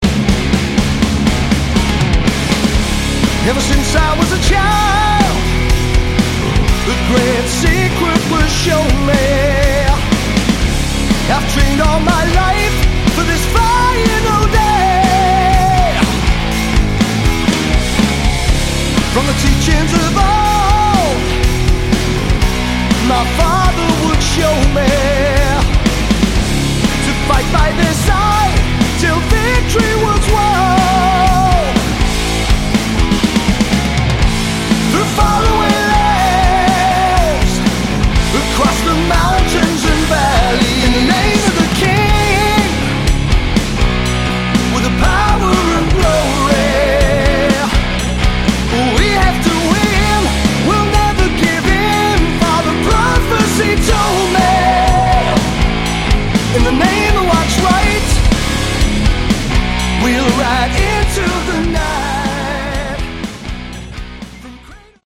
Category: Hard Rock
lead and backing vocals, bass, guitars
electric, acoustic and slide guitars
drums
kayboards, backing vocals